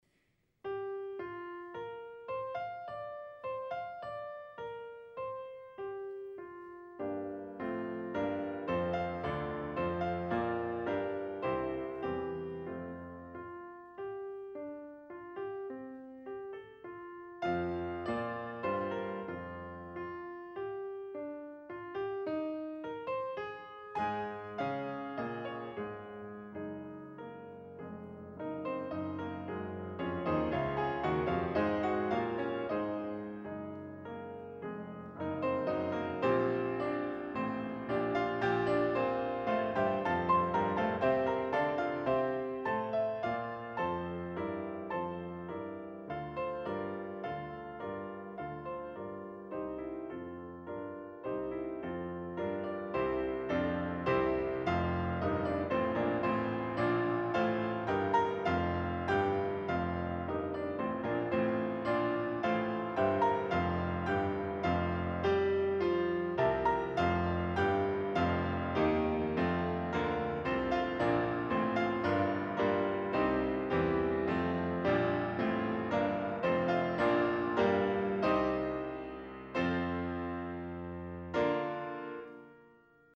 Additional Date(s)Recorded May 22, 1981 in the Ed Landreth Hall, Texas Christian University, Fort Worth, Texas
Suites (Piano)
Short audio samples from performance